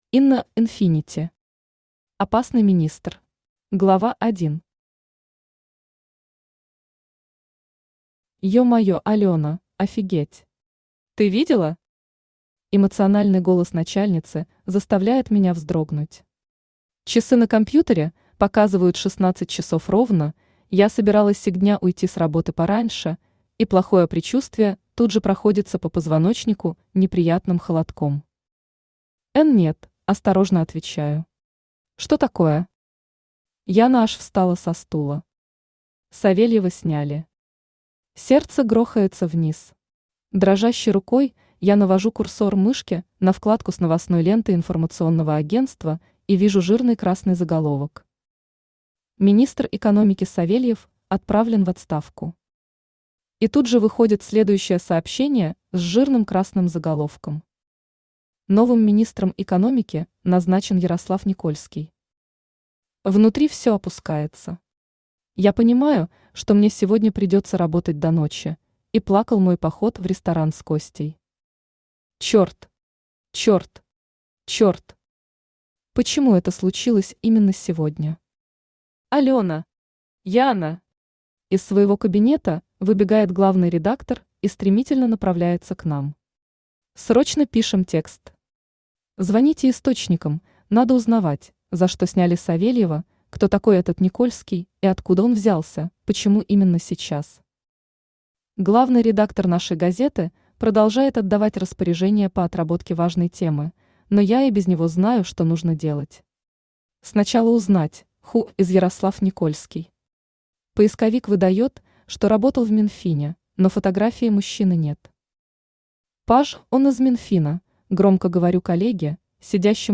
Аудиокнига Опасный министр | Библиотека аудиокниг
Aудиокнига Опасный министр Автор Инна Инфинити Читает аудиокнигу Авточтец ЛитРес.